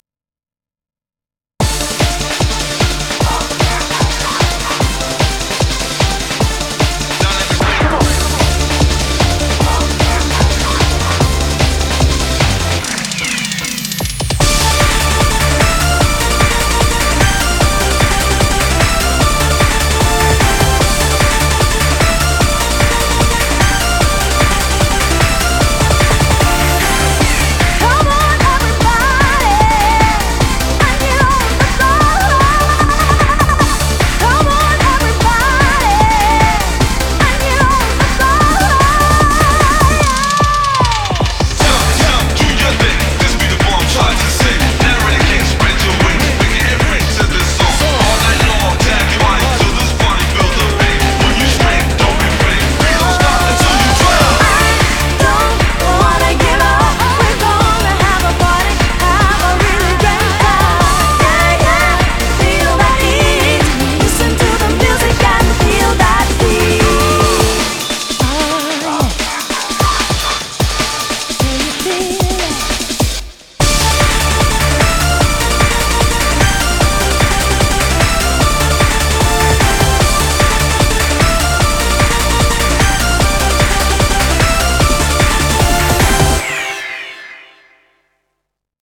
BPM150